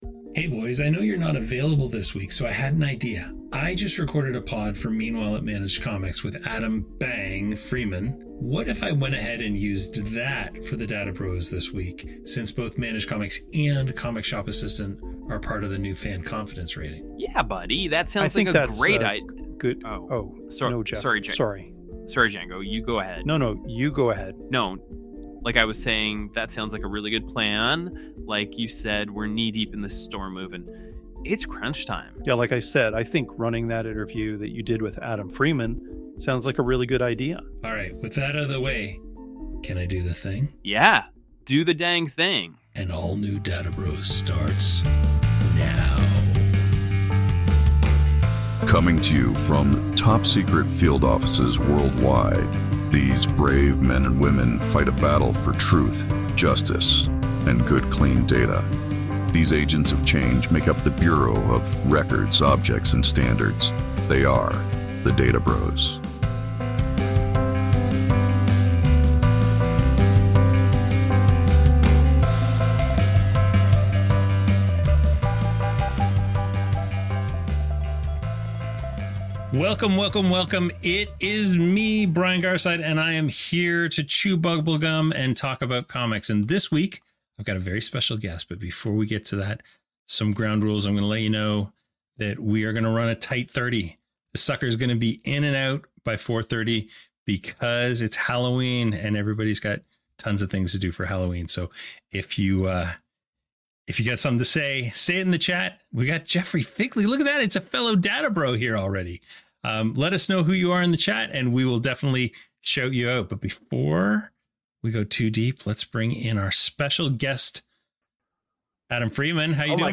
THRILL at two bald dudes talking data the way only bald dudes talking data can!